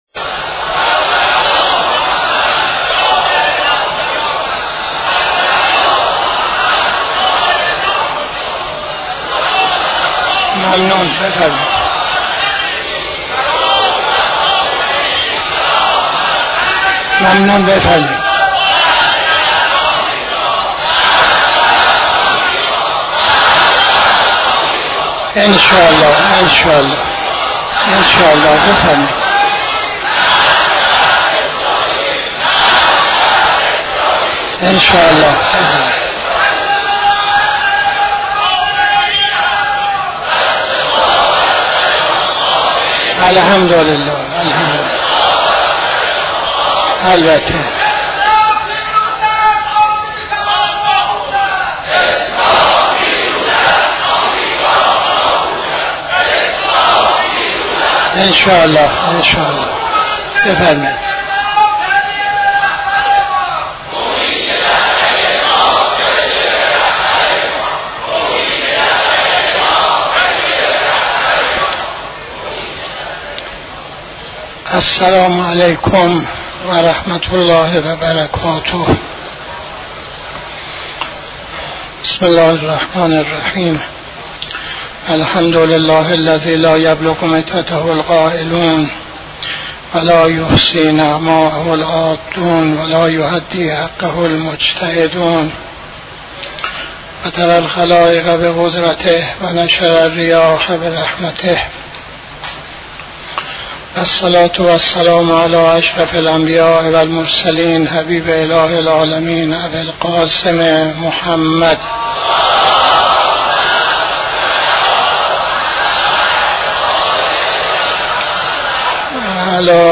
خطبه اول نماز جمعه 27-06-83